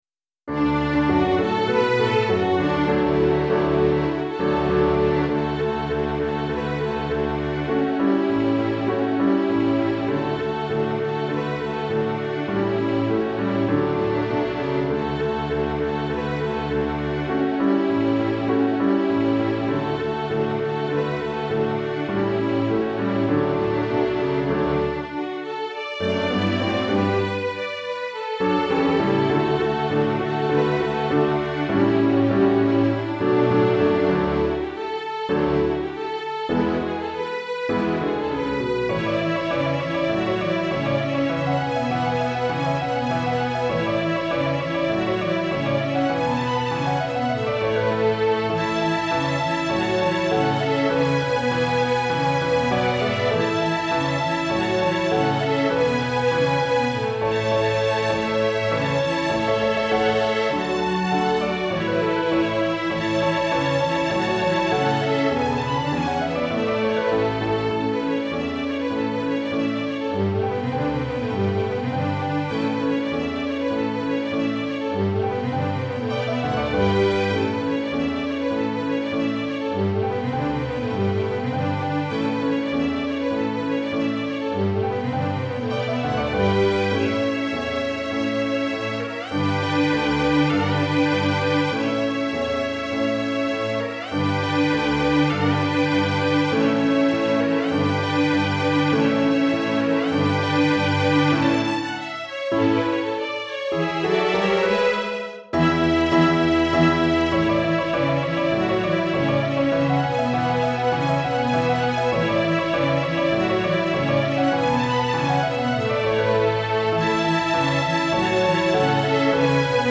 Recueil pour Musique de chambre